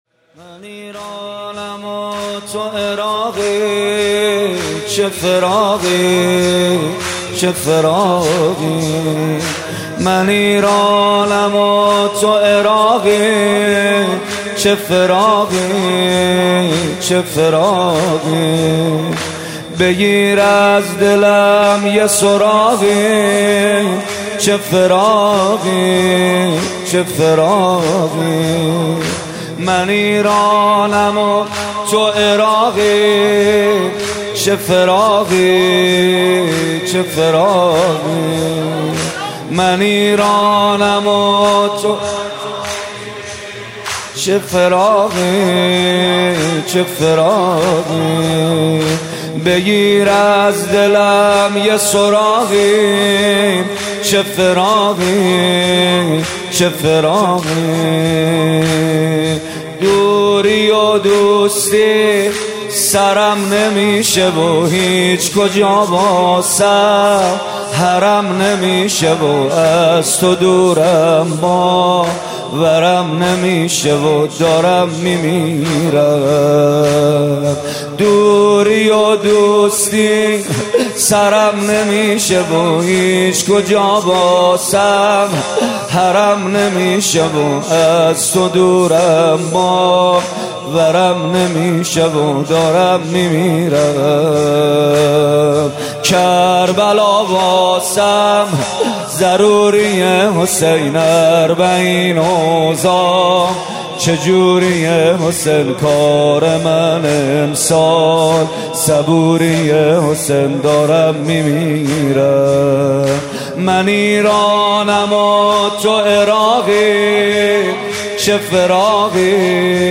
نوحه